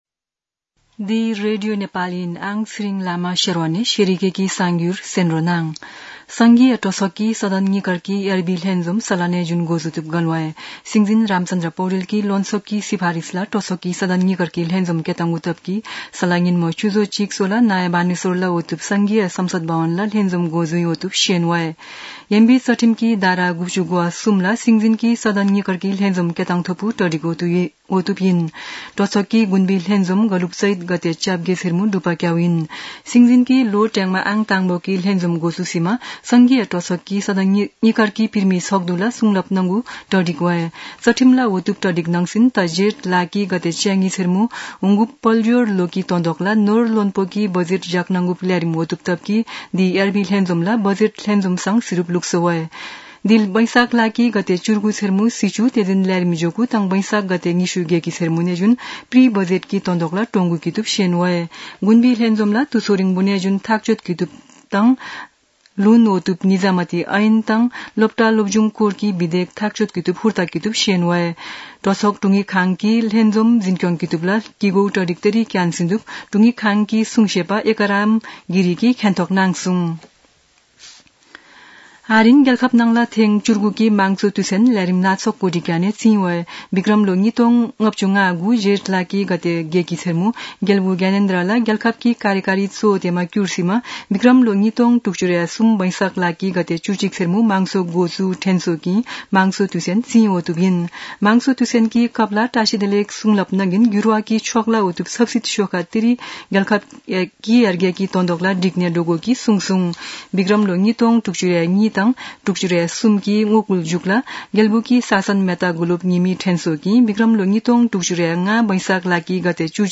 शेर्पा भाषाको समाचार : ११ वैशाख , २०८२
sharpa-news-1.mp3